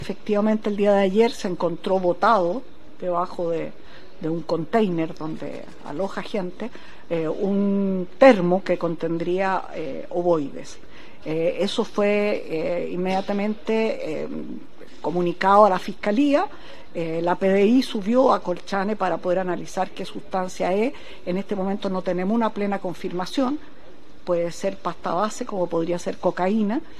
Por su parte, la ministra de Defensa, Adriana Delpiano, informó que la sustancia fue hallada bajo un container utilizado como alojamiento dentro del regimiento.